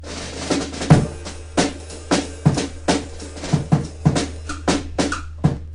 Исходный луп барабанов звучит просто: это глушёная ударная установка с приличным количеством комнаты в микрофонах.
Вот исходник барабанов: Вложения Lonely Soul_dr.mp3 Lonely Soul_dr.mp3 227,5 KB · Просмотры: 536